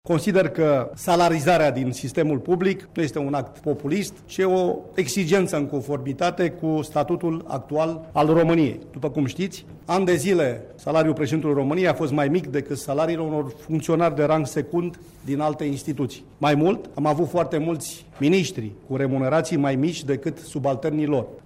Premierul interimar, Gabriel Oprea, declara că îşi asumă atât corectitudinea, cât şi oportunitatea acestei măsuri :